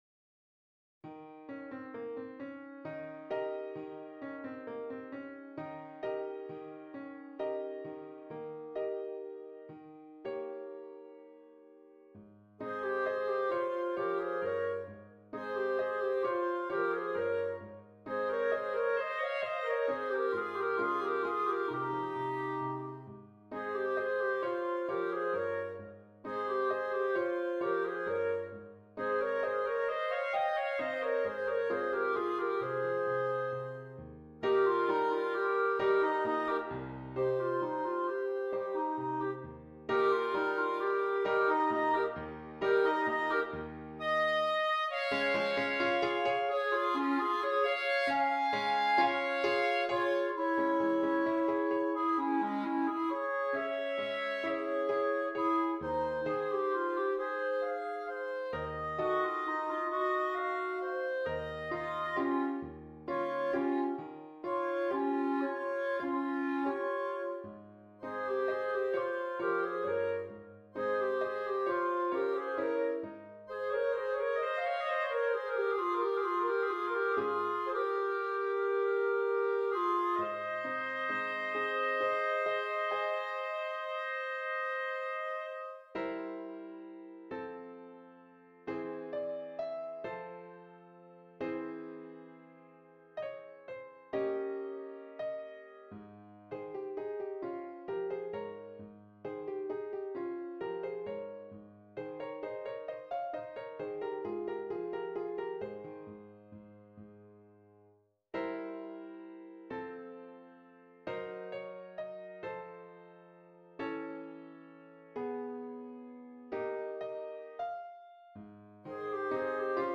2 Clarinets and Keyboard